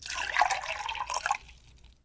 pourmilk1.ogg